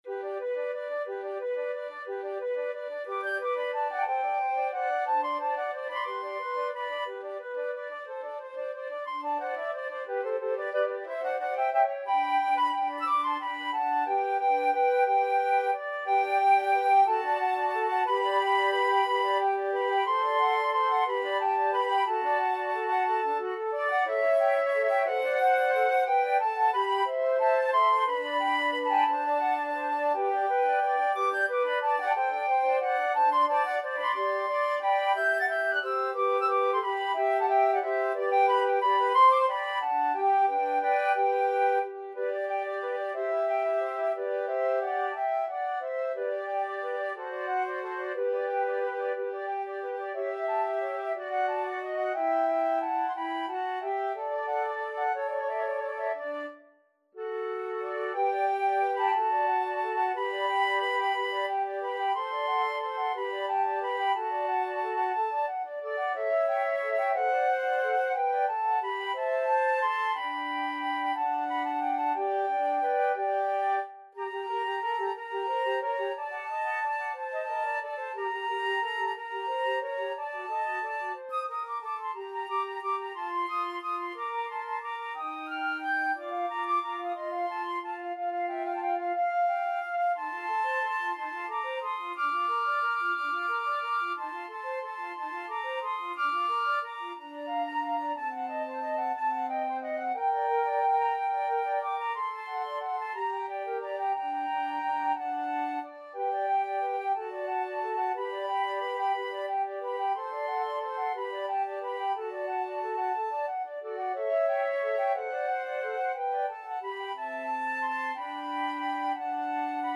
Free Sheet music for Flute Trio
Flute 1Flute 2Flute 3
6/8 (View more 6/8 Music)
G major (Sounding Pitch) (View more G major Music for Flute Trio )
Molto lento .=c. 60
Classical (View more Classical Flute Trio Music)